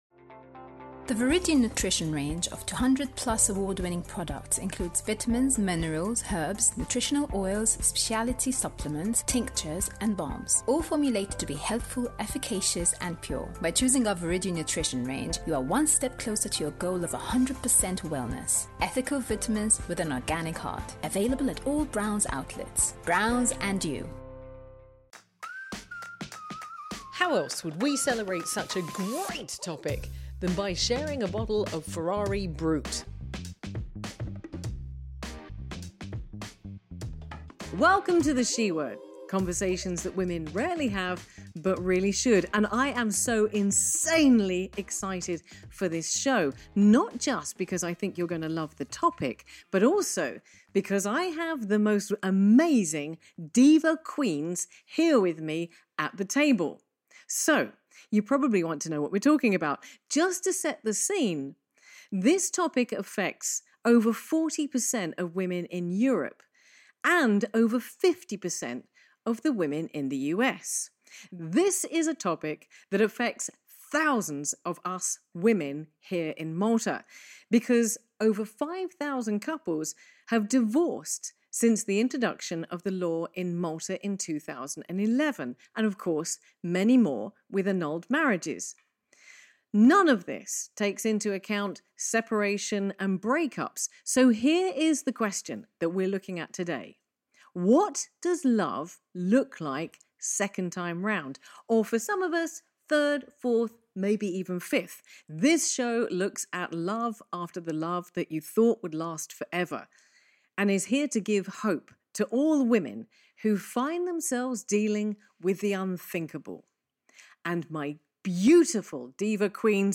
The women at the table share their stories of dysfunctional relationships and bad breakups.